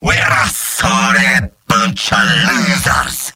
Robot-filtered lines from MvM. This is an audio clip from the game Team Fortress 2 .
Demoman_mvm_jeers11.mp3